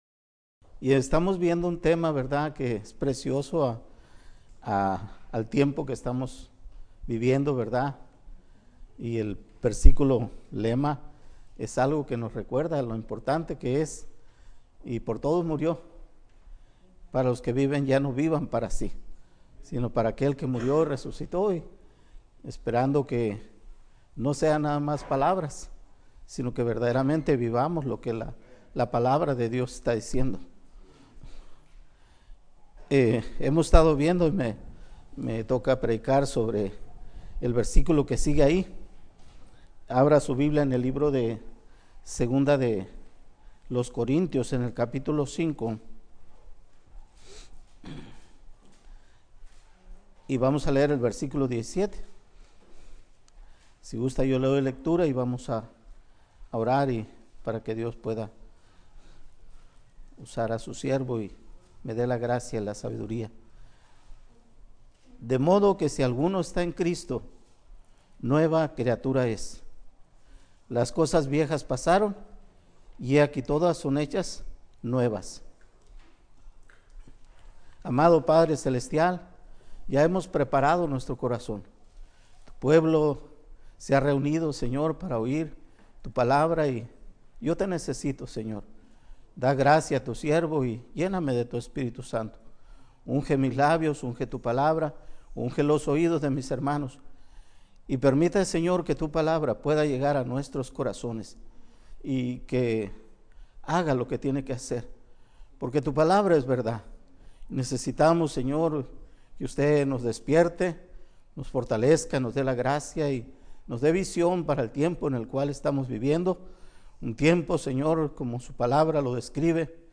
Conferencia Bíblica 8.5